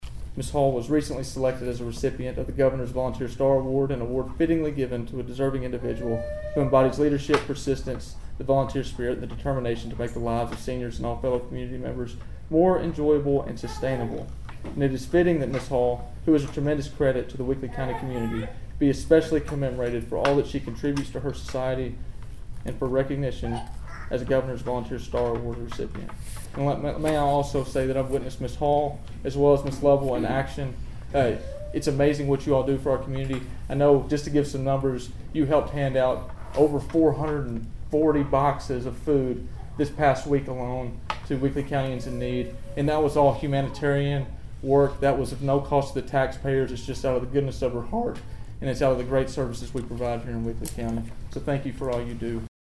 Weakley County Mayor Dale Hutcherson delivered proclamations at this month’s Weakley County Commission meeting.